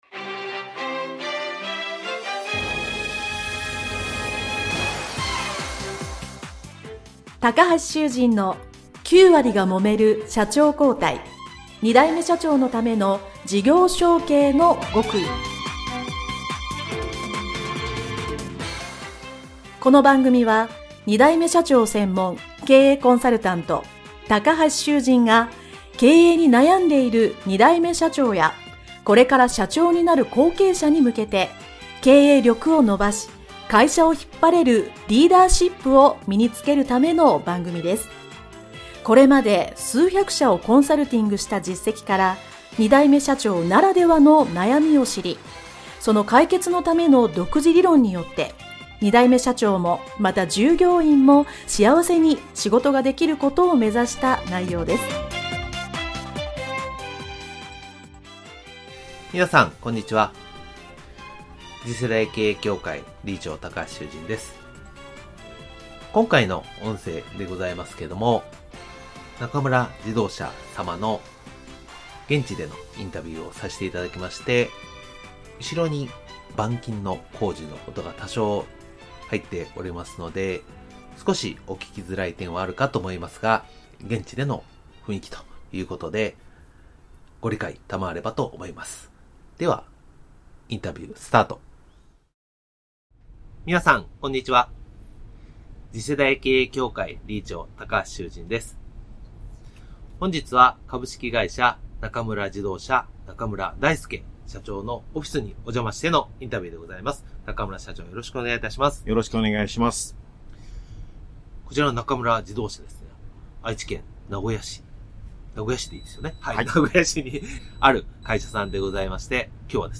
【インタビュー前編】